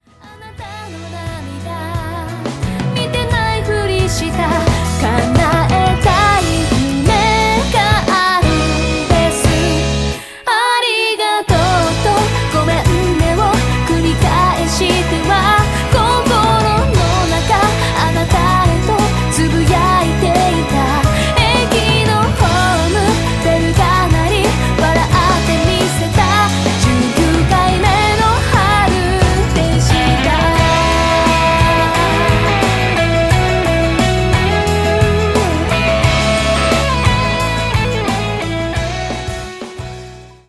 歌詞に込められたメッセージと飾らない真っ直ぐで優しくも力強い歌声。
そして、フルートの音色が心地よく心に響き、極彩色の音と言葉が、夢と勇気と希望を与えてくれる一枚！